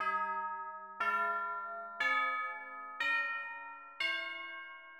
Pelog barang.[1]
Pelog_barang.mid.mp3